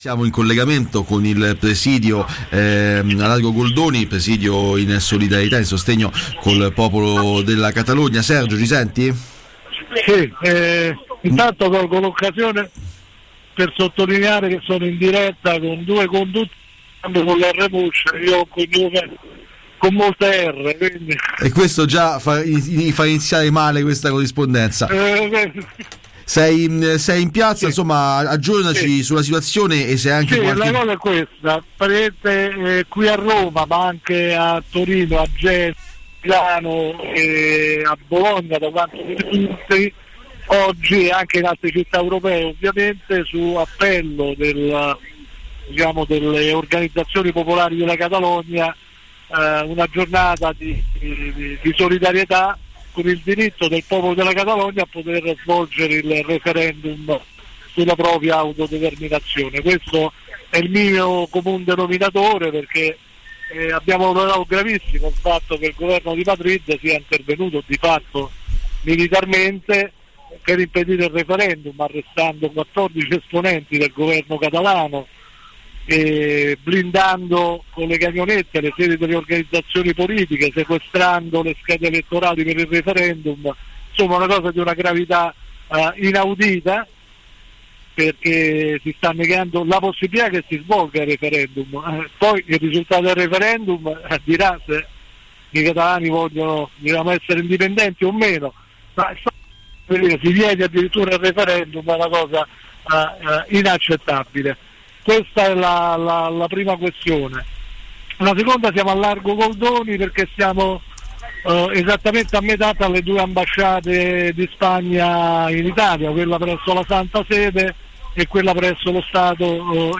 Catalogna: corrispondenza dal presidio Eurostop a Roma